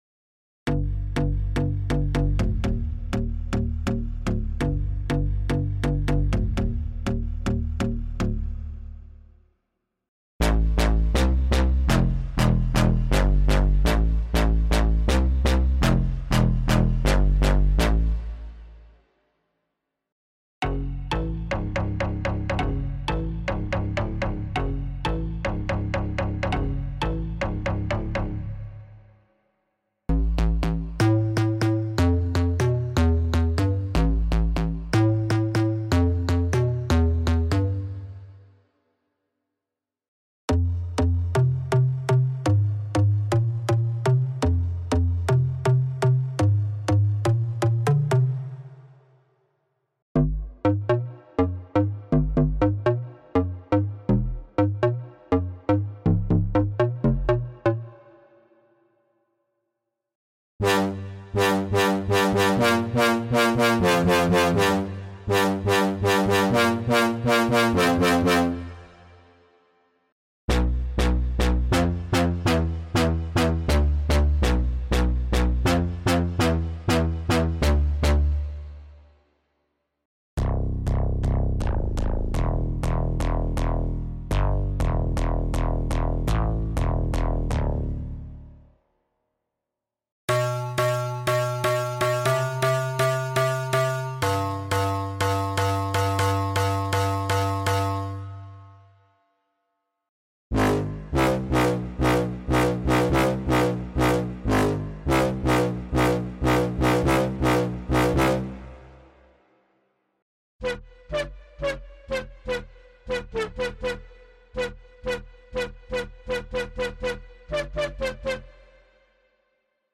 Muze Bass Elements是一款专为音乐、声音设计和电影配乐而打造的模拟和数字低音合成器。它拥有超过1400种不同风格的低音声音，涵盖了House, Hip-Hop, EDM, Techno等流行音乐类型。：
- 每个预设有两层声源，可以从2到144种声音中混合选择，创造出独特的低音效果。
Muze Bass Elements是一款适合各种音乐制作和创意项目的低音合成器，可以提供丰富的低音色彩和动感。